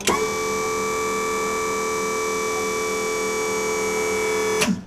Index of /90_sSampleCDs/E-MU Producer Series Vol. 3 – Hollywood Sound Effects/Human & Animal/Cassette Door
CASSETTE 00L.wav